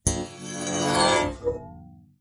描述：金属效果使用台虎钳固定锯片和一些工具来击打，弯曲，操纵。 所有文件都是96khz 24bit，立体声。
Tag: 研磨 尖叫 金属 耐擦 效果 声音